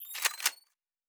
pgs/Assets/Audio/Sci-Fi Sounds/Weapons/Weapon 10 Reload 1.wav at master
Weapon 10 Reload 1.wav